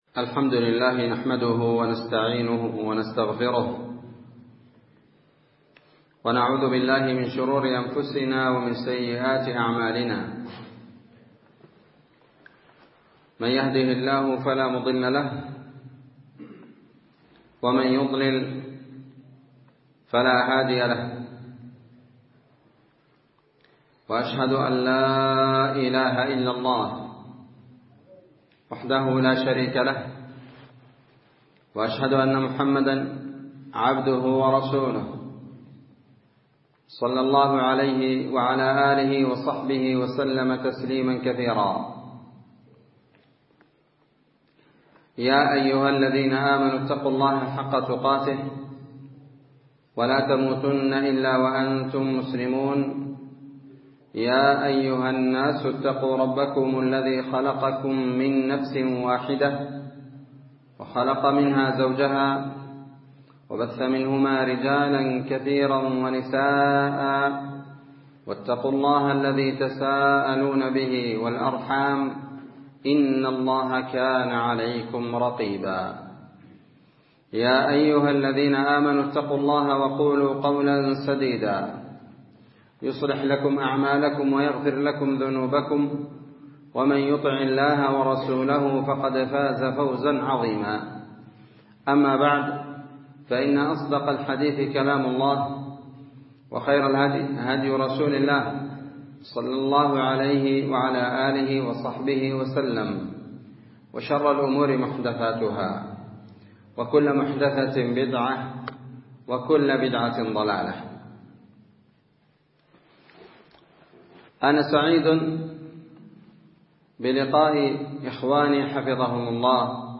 محاضرة بعنوان ( استنباطات معاني الثبات من بعض الآيات) 7 جمادى الآخرة 1444